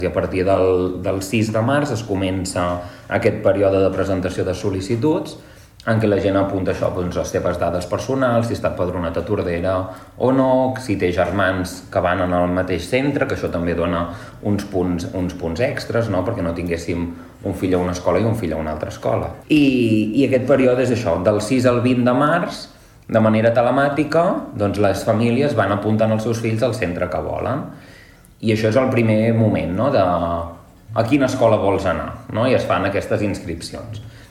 Avui és el darrer dia per fer la preinscripció en educació infantil, primària i secundària. Explica el procés, el regidor d’ensenyament, Pere Garcia.